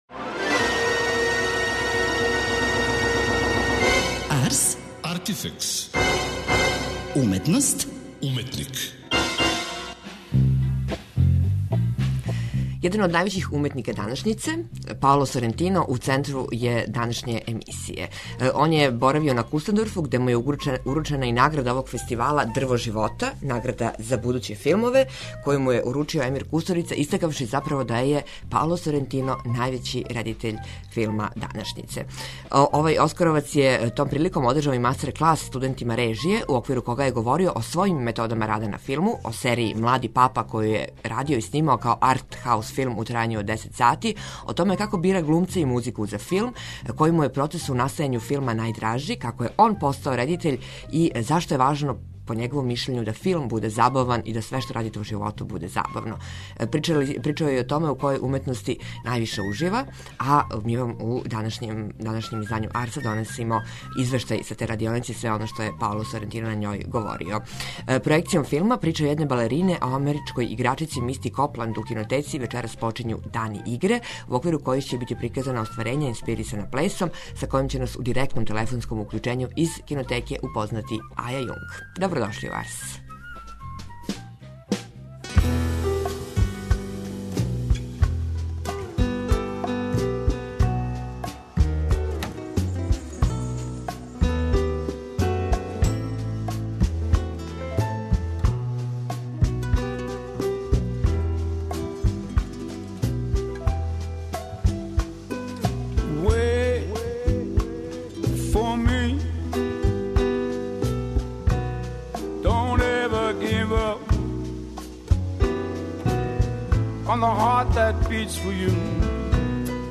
Интервју: Паоло Сорентино